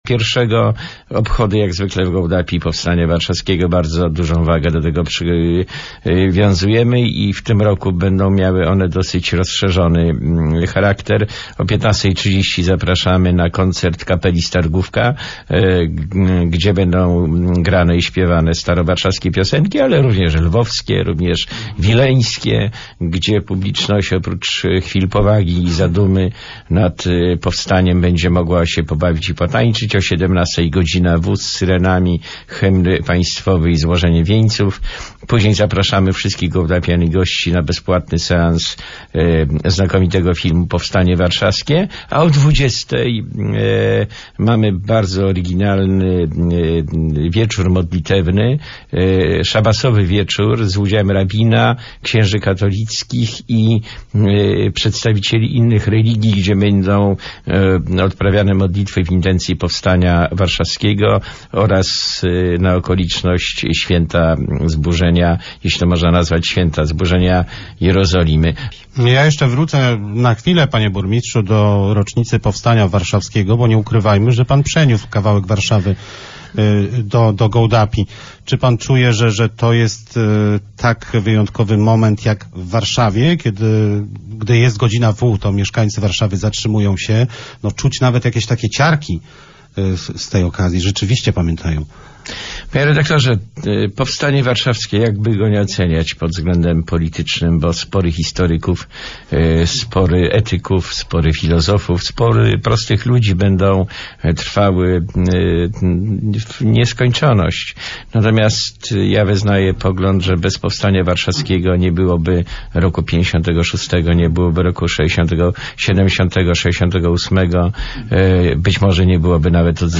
o obchodach 70 rocznicy wybuchu Powstania Warszawskiego mówi Marek Miros, burmistrz Gołdapi
(fragment audycji radiowej, Radio 5, 21 lipca 2014)